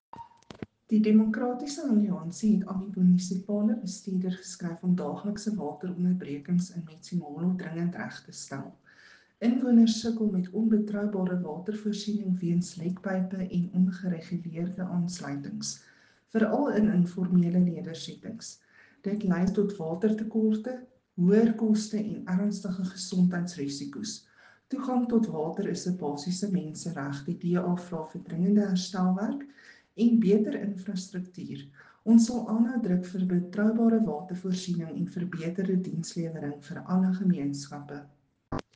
Afrikaans soundbite by Cllr Ruanda Meyer and